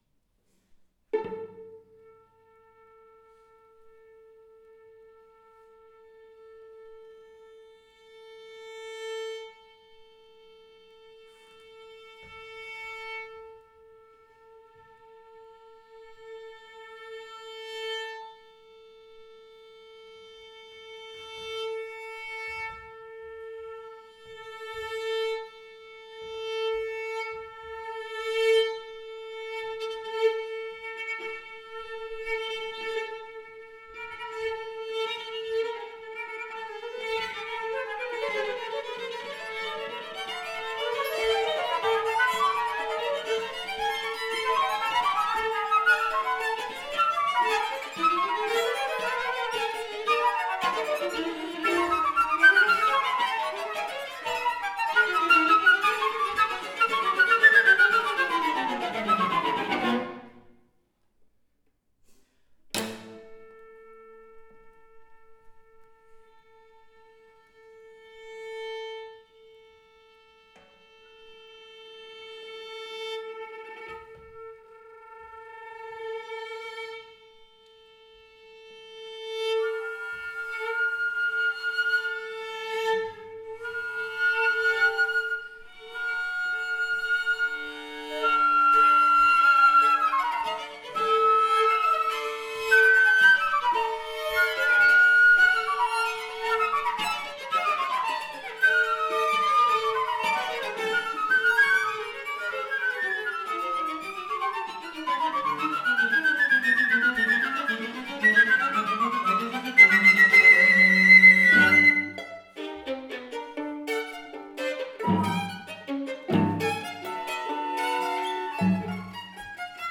Terrific venue with marvelous acoustics.
This recording is from the Budapest premiere of my composition, “Two Mirrors” for flute and string trio.
violin
viola
cello.